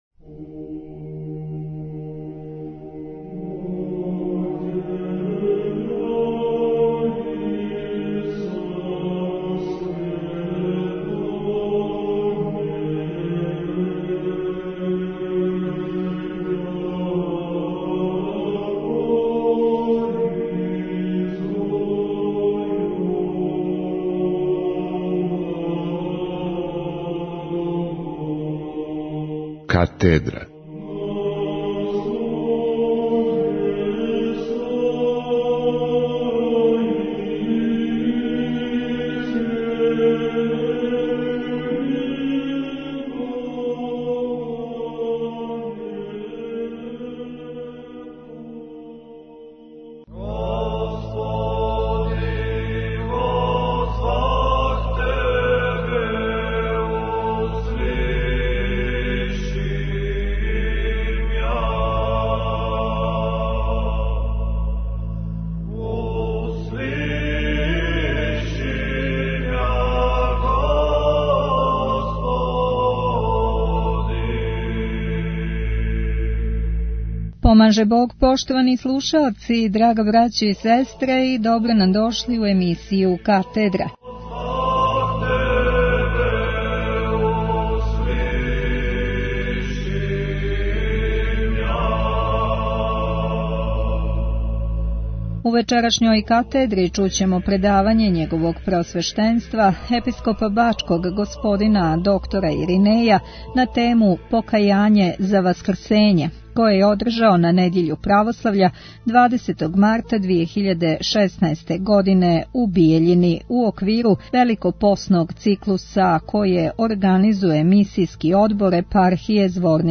Епископ Иринеј Буловић одржао предавање у Бијељини | Радио Светигора
У Недјељу Православља 20. марта 2016. године, Епископ бачки Иринеј одржао је предавање на тему "Покајање за васкрсење" у Бијељини са почетком у 17:00 часова.
На крају предавања, Епископ зворничко-тузлански г. Хризостом захвалио је Епископу Иринеју на одличном предавању, које је било јасно и прецизно изложено.